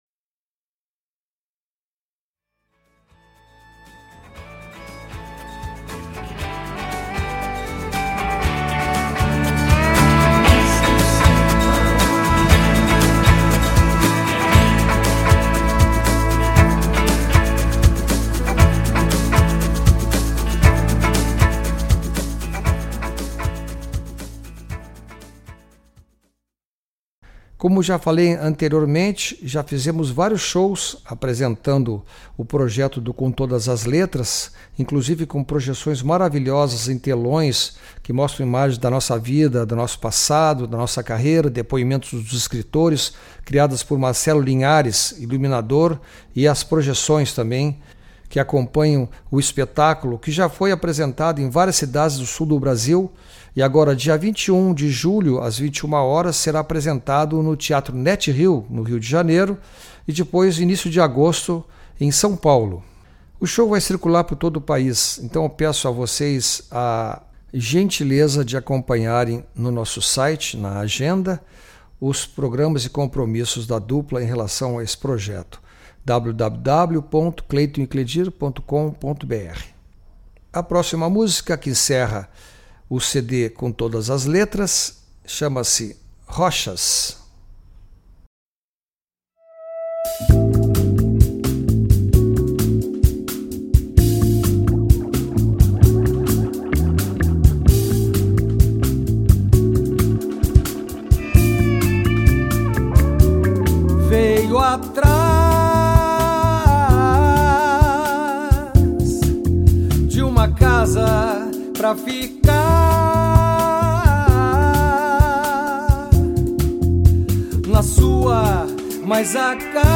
solo de sax
violão e guitarra